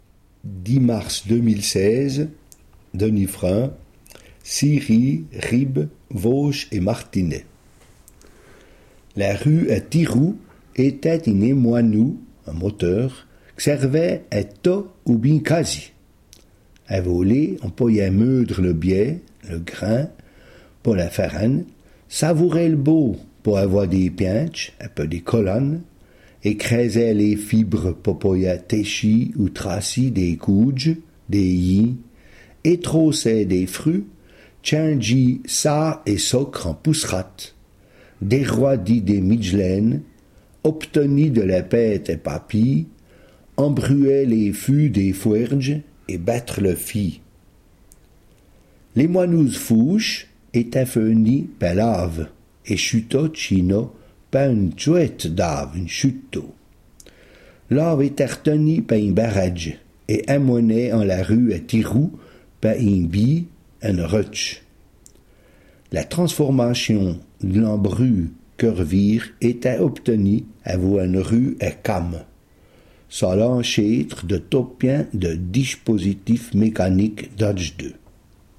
Ecouter le r�sum� en patois